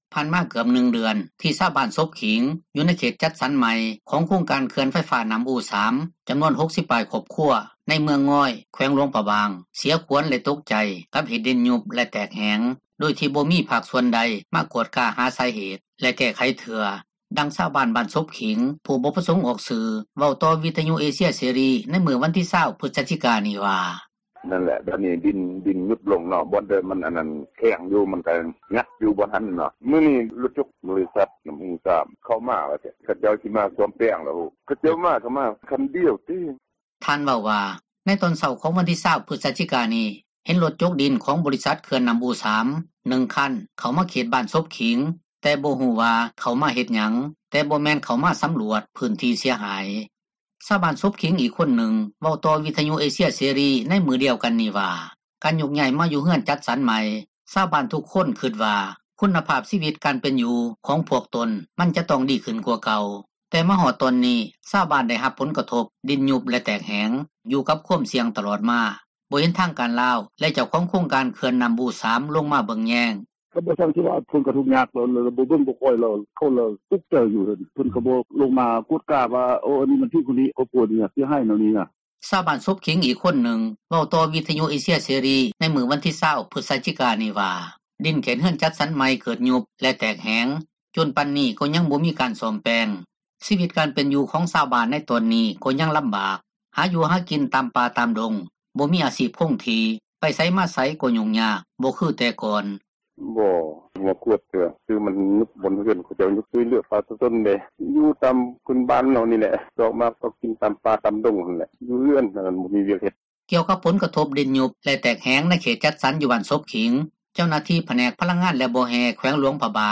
ຜ່ານມາເກືອບ 1 ເດືອນ ທີ່ຊາວບ້ານສົບຂີງ ຢູ່ເຂດຈັດສັນໃໝ່ ຂອງໂຄງການເຂື່ອນໄຟຟ້ານໍ້າອູ 3 ຈໍານວນ 60 ປາຍຄອບຄົວຢູ່ເມືອງງອຍ ແຂວງຫລວງພຣະບາງ ຂັວນເສັຍແລະຕົກໃຈ ກັບເຫດດິນຍຸບ ແລະ ແຕກແພງ ໂດຍທີ່ຍັງບໍ່ມີພາກສ່ວນໃດ ມາກວດກາຫາສາເຫດ ແລະ ແກ້ໄຂໃຫ້ເທື່ອ, ດັ່ງຊາວບ້ານ ບ້ານສົບຂີງ ຜູ້ບໍ່ປະສົງອອກຊື່ ເວົ້າຕໍ່ ວິທຍຸເອເຊັຍເສຣີ ໃນມື້ວັນທີ 20 ພຶສຈິກາ ນີ້ວ່າ: